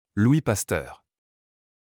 Louis Pasteur ForMemRS (/ˈli pæˈstɜːr/, French: [lwi pastœʁ]
Fr-Louis_Pasteur.wav.mp3